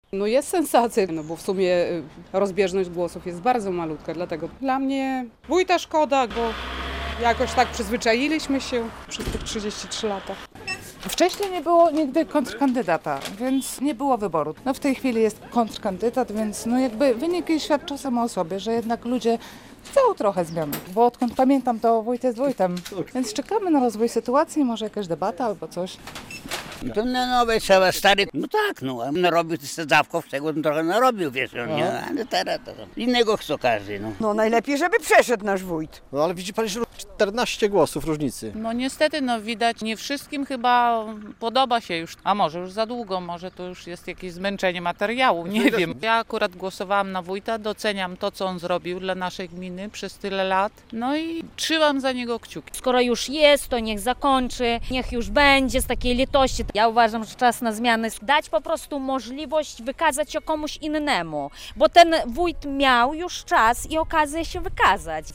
relacja
Pytani przez nas mieszkańcy przyznają, że tegoroczne wybory były ciekawe, bo po raz pierwszy wieloletni wójt miał kontrkandydatów.